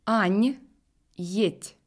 En fin de mot, la consonne est molle si elle est suivie du signe mou "ь":